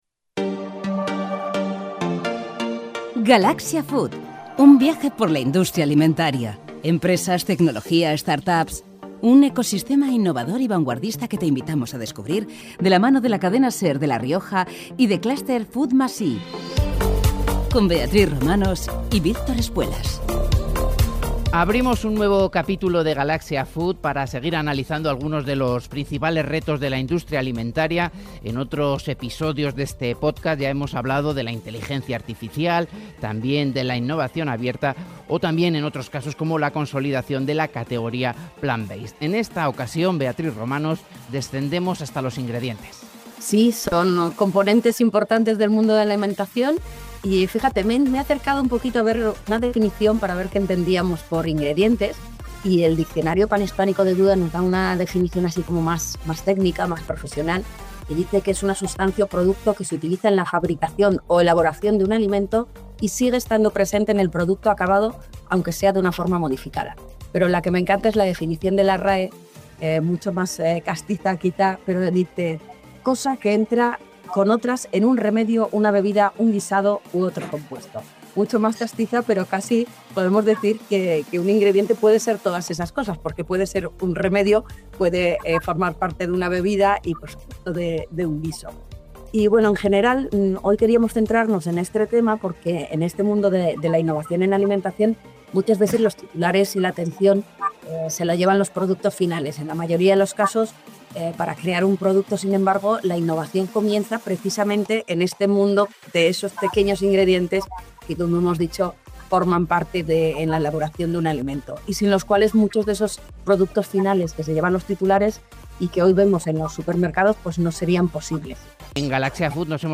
En Galaxia Food hemos conversado con tres profesionales para conocer las líneas […]